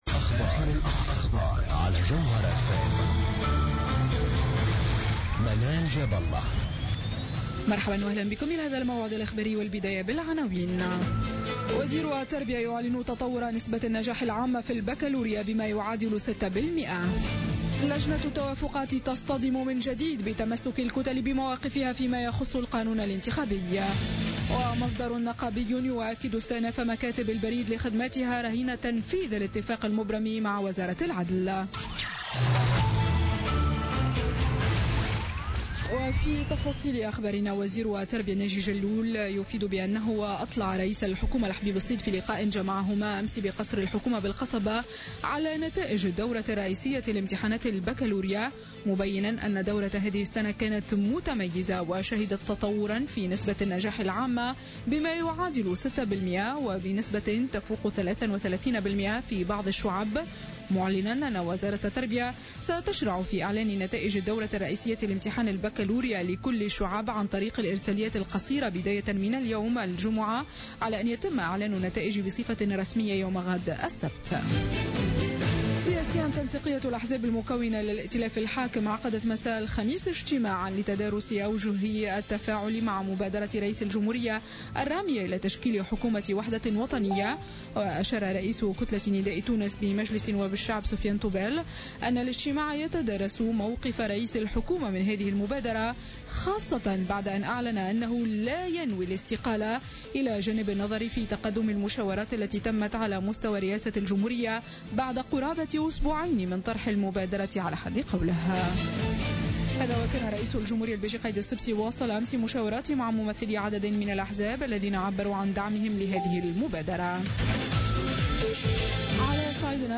Journal info 00h00 du vendredi 17 juin 2016